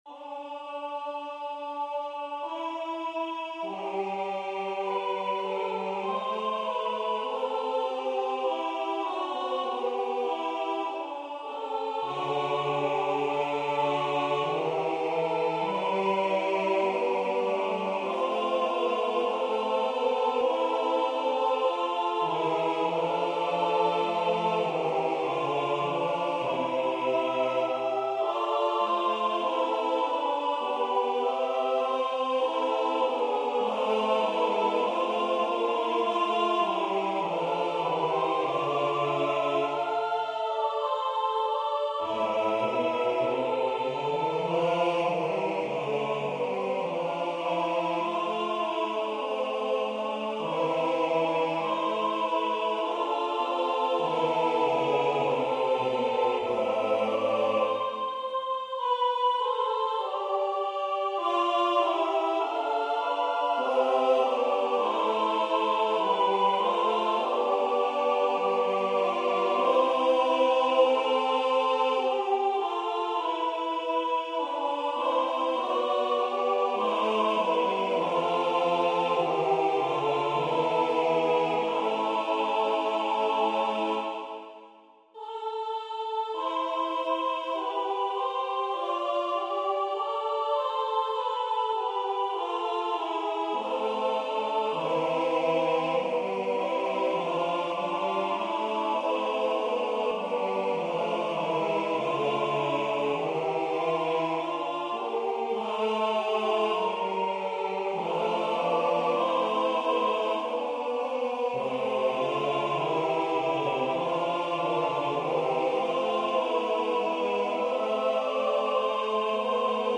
A computer generated sound file is included.
Voicing/Instrumentation: SATB